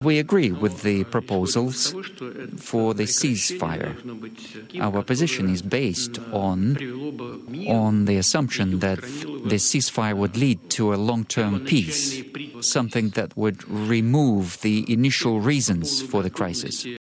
He also claims the Kursk region is ‘completely under’ Moscow’s control now, and that his forces are ‘on the offensive’: